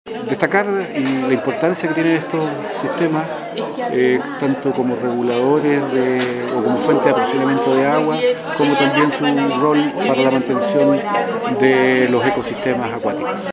En el marco del Día Mundial del Agua, cada 22 de marzo, el Centro de Recursos Hídricos para la Agricultura y la Minería, Crhiam, organizó una nueva edición del Foro del Agua, con el título “Salvemos nuestros glaciares”.